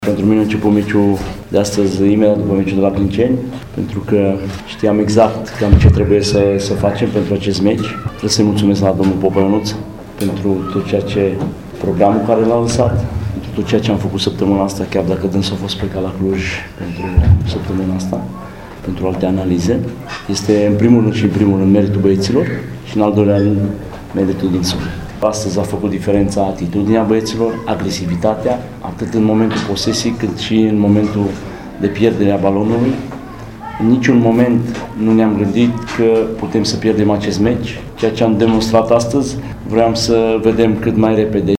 a spus la conferința de presă